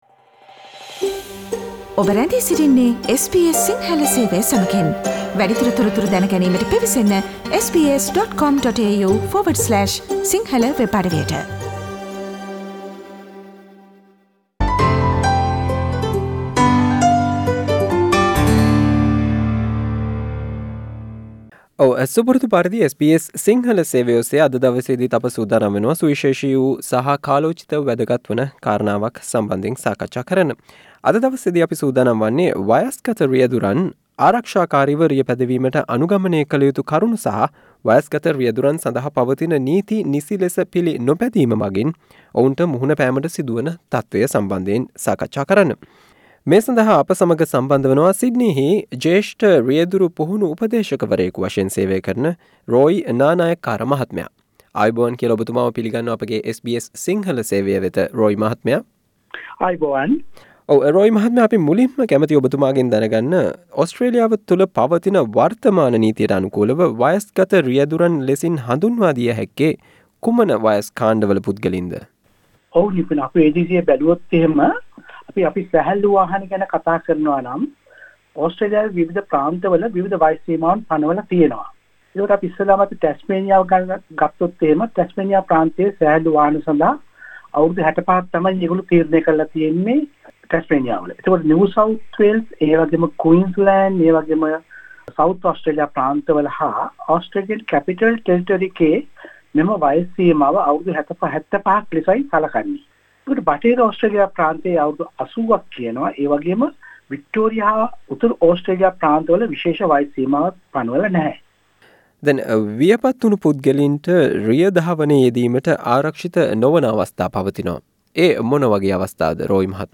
SBS interview